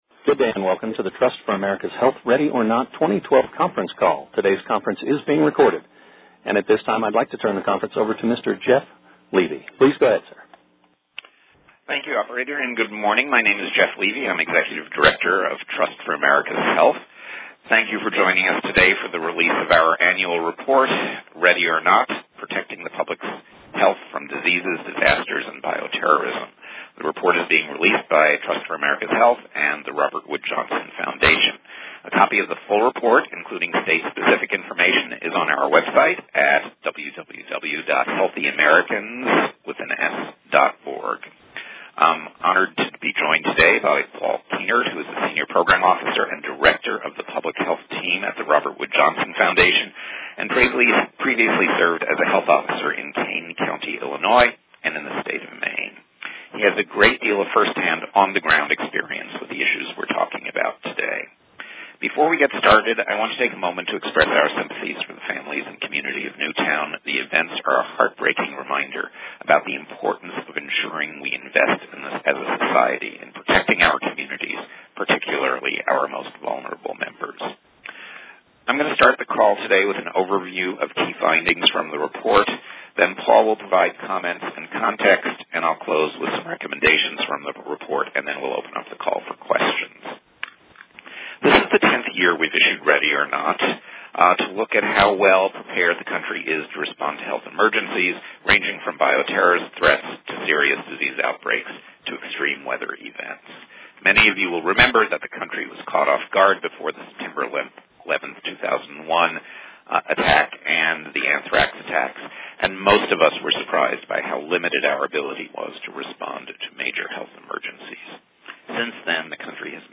Resources Full Report National News Release Recording of the News Conference Call Discover State Releases Explore state-level releases on this report by choosing from the drop-down menu below.